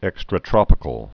(ĕkstrə-trŏpĭ-kəl)